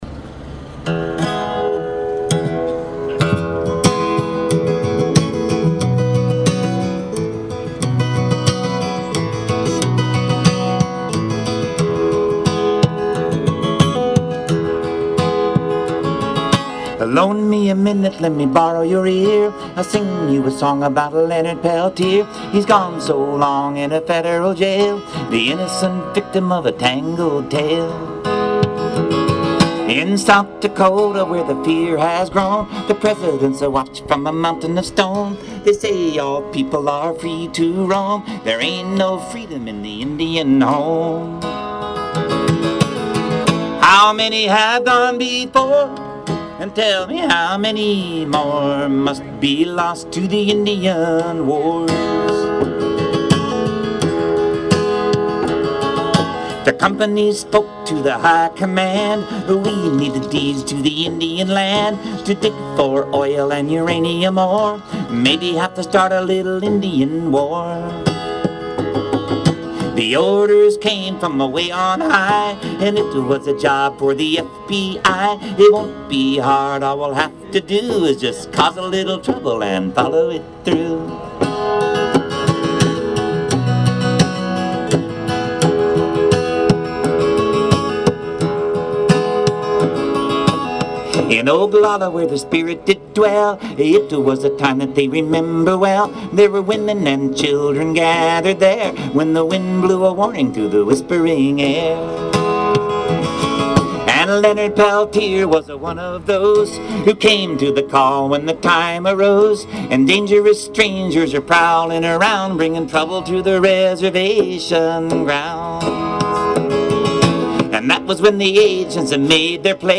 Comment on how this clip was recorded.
a rally for the Native American political prisoner